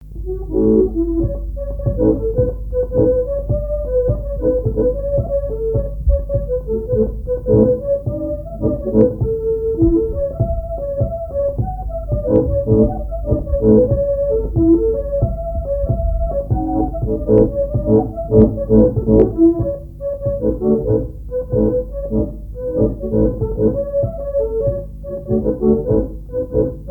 danse : pas de quatre
Répertoire à l'accordéon diatonique
Pièce musicale inédite